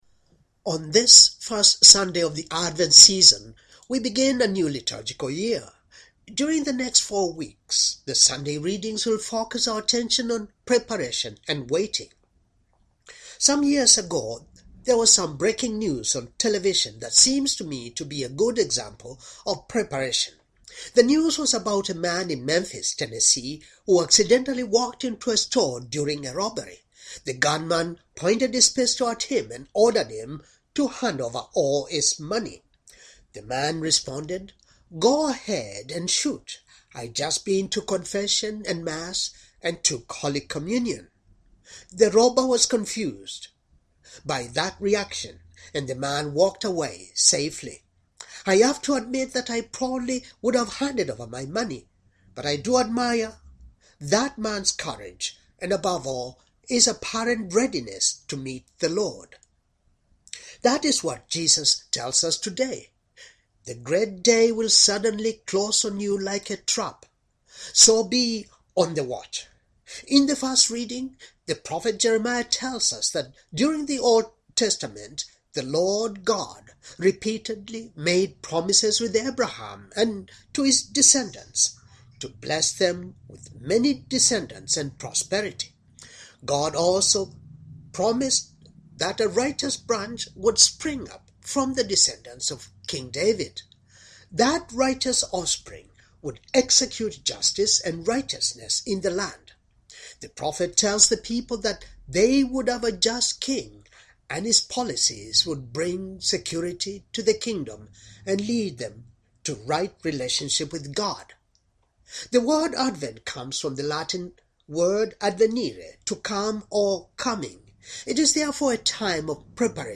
homily, first,sunday,advent,yearc,